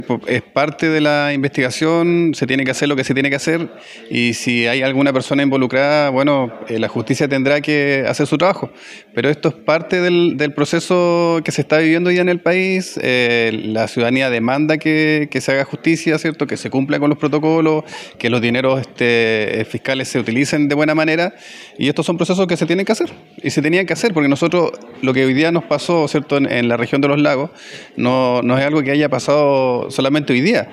Mientras que el Core de la provincia de Llanquihue, Cristian Vargas, indicó que es necesario cumplir con los protocolos y si hay alguna persona involucrada en irregularidades la justifica deberá hacer su trabajo.